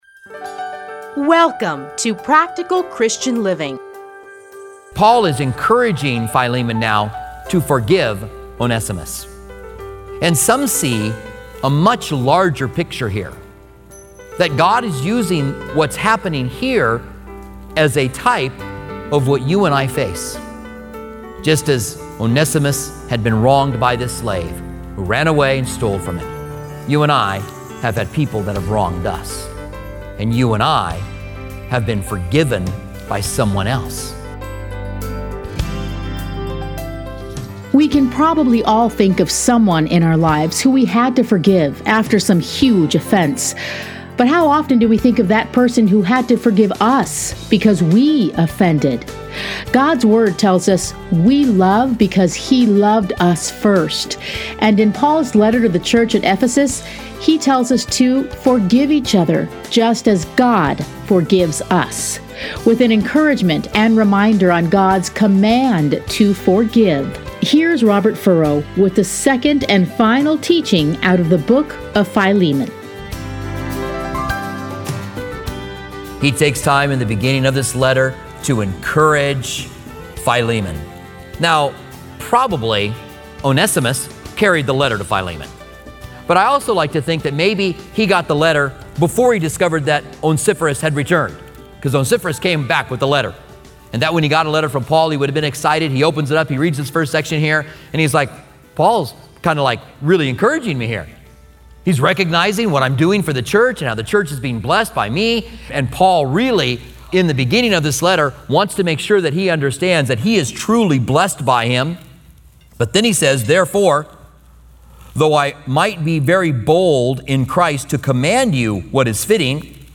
Listen here to a teaching from Philemon.